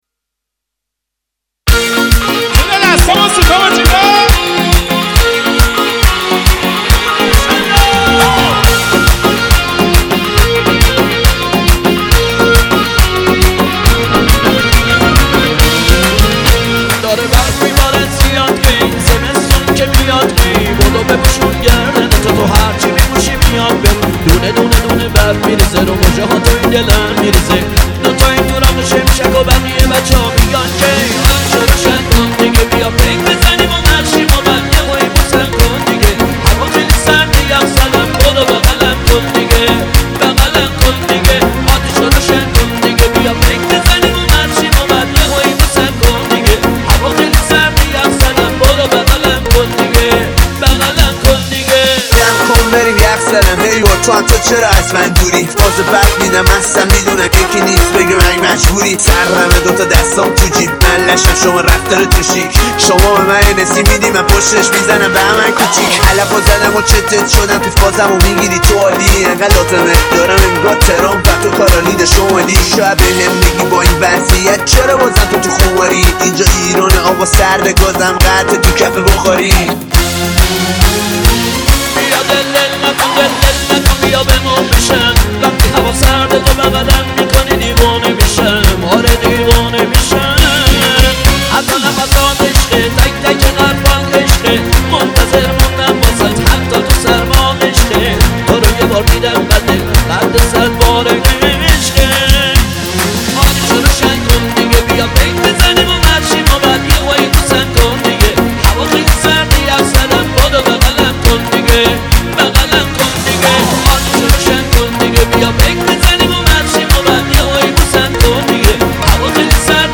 محلی
آهنگ با صدای زن